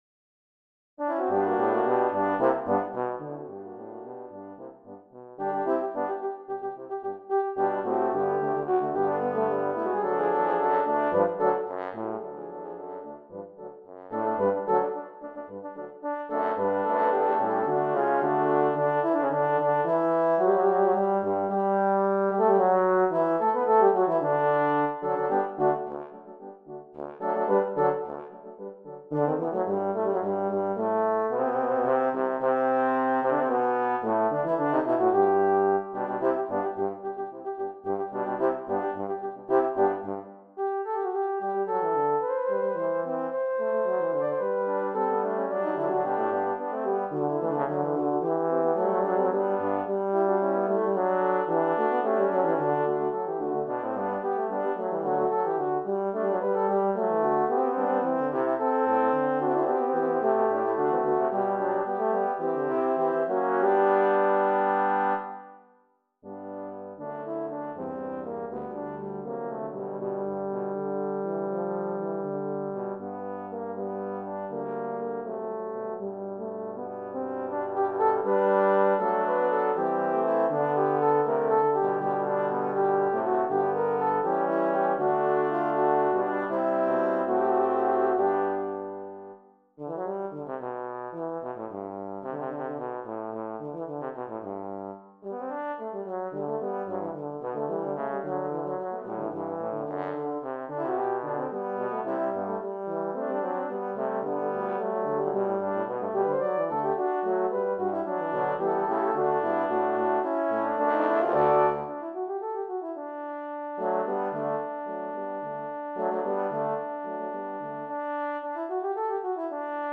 This is a head-on collision between baroque, jazz, and technical ad-libbing using Handel's Hallelujah Chorus as my template. If Berlioz can call one of his works Fantastique, so can I. See if you can hear the Wagner snippet towards the end.